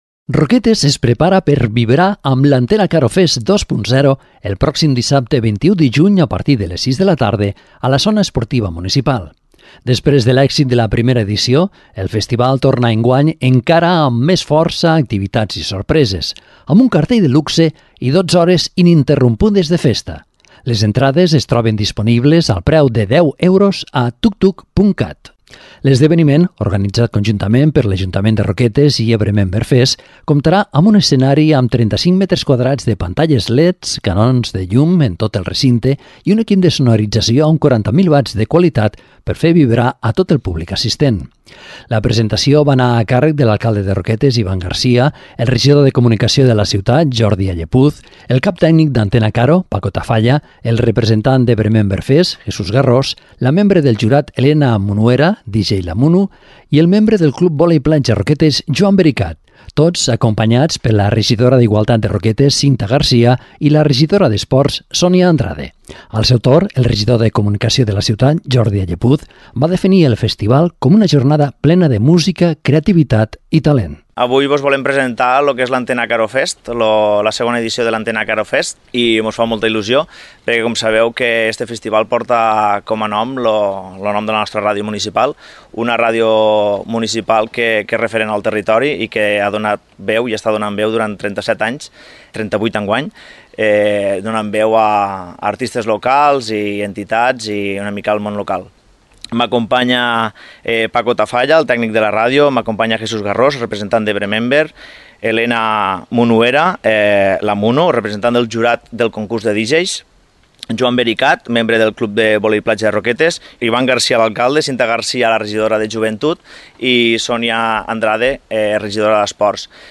Presentacio-de-la-2a-edicio-del-festival-Antena-Caro-Fest-a-Roquetes-web.mp3